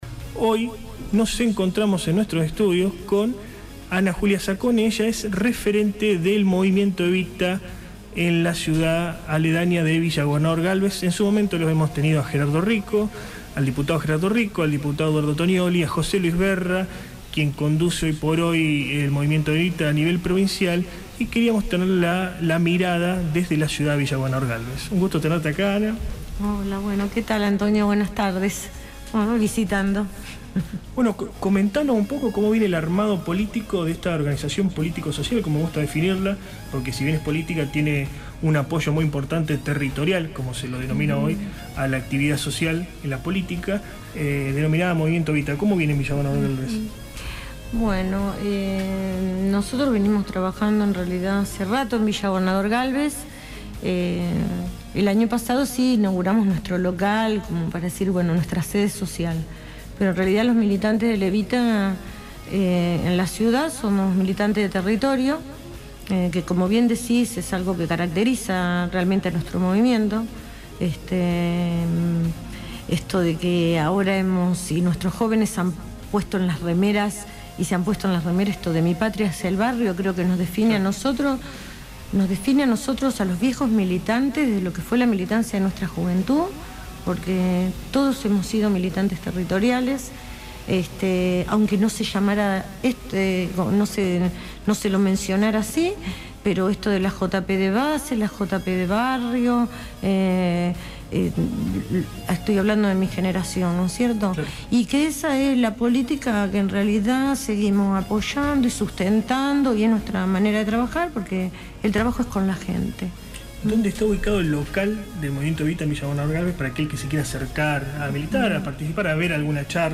AUDIO ENTREVISTA